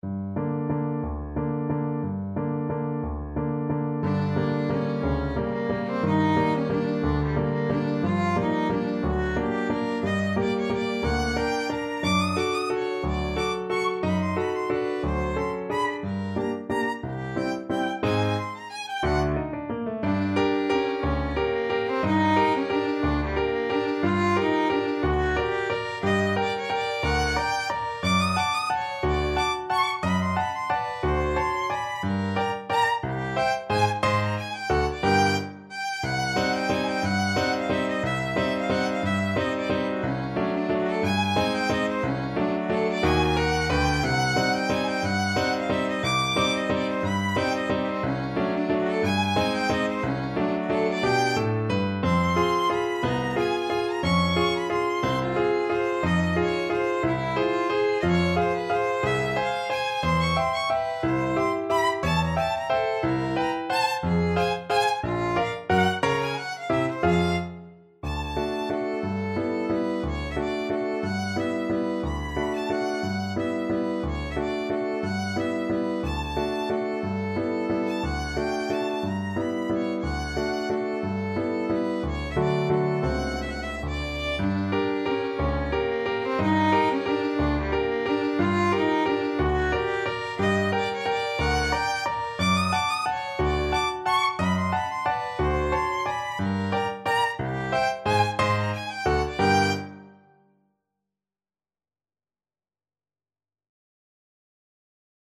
Violin version
3/4 (View more 3/4 Music)
Allegro espressivo .=60 (View more music marked Allegro)
Classical (View more Classical Violin Music)